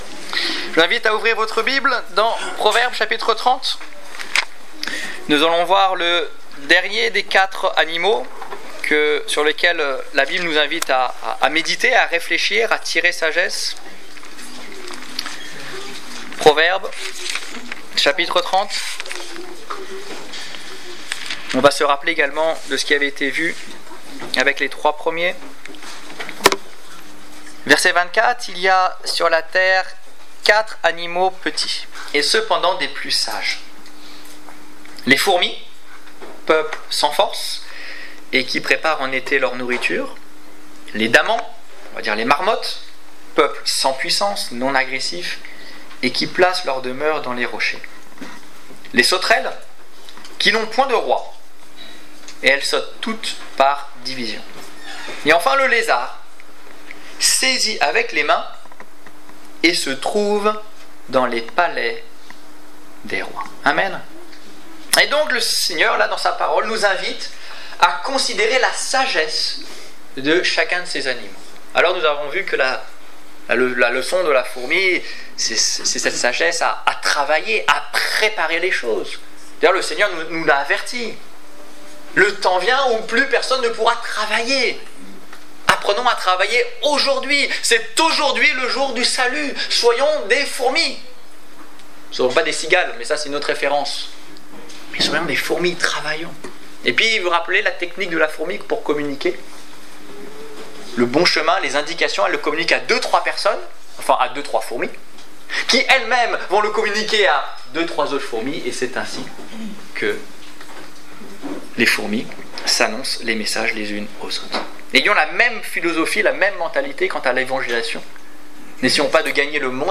4 animaux sages (4) - Le lézard Détails Prédications - liste complète Culte du 11 septembre 2016 Ecoutez l'enregistrement de ce message à l'aide du lecteur